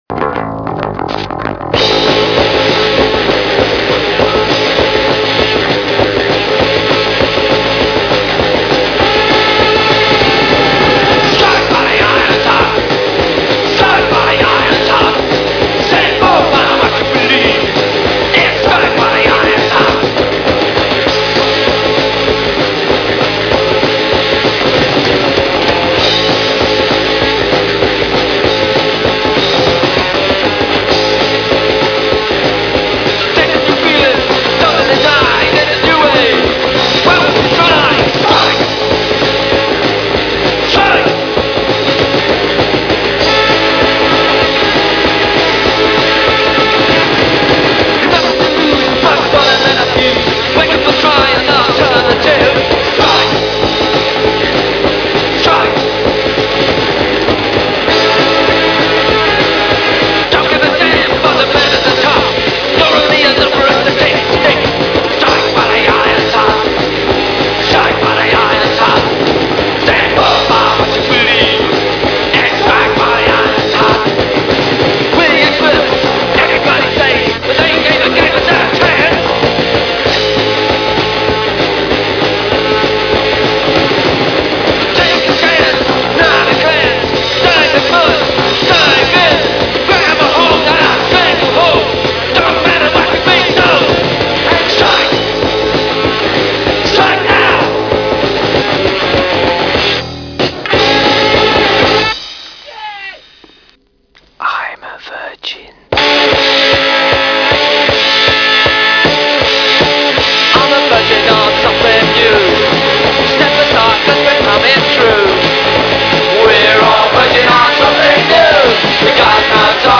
に若干ノイズが入ります。